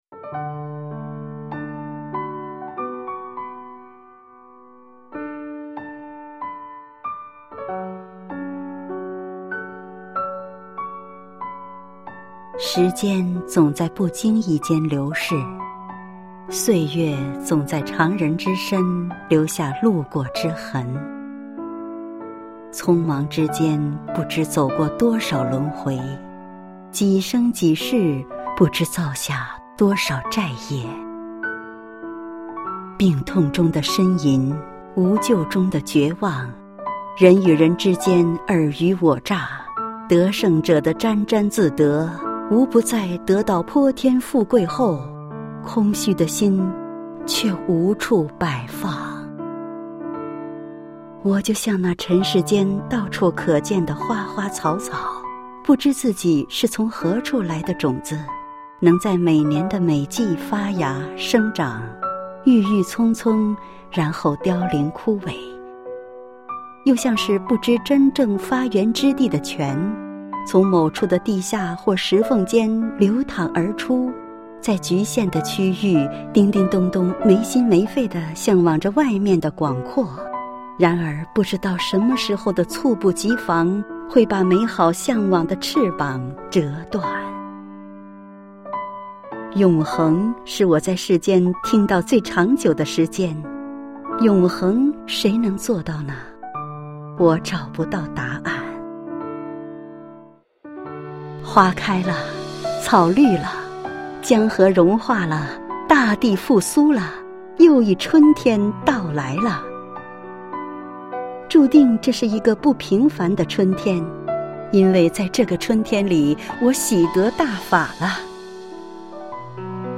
配樂詩朗誦（音頻）：最幸福的時刻